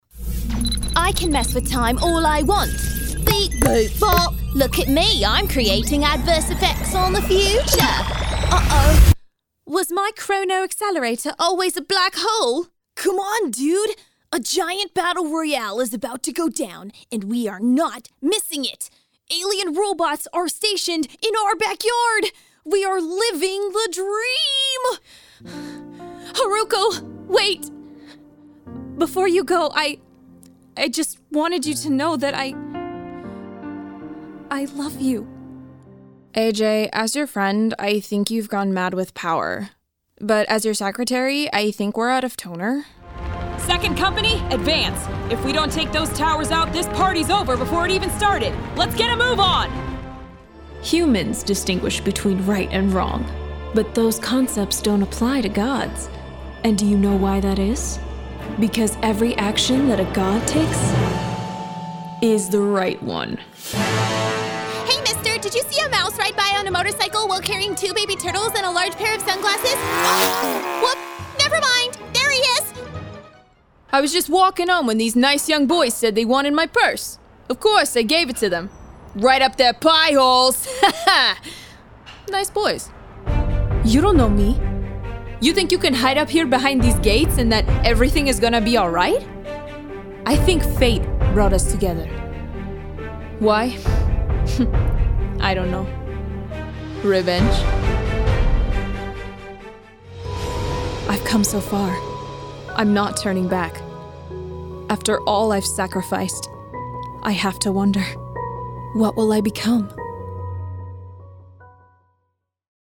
Female Voice Over, Dan Wachs Talent Agency.
Sincere, Animated, Real, Youthful.
Animation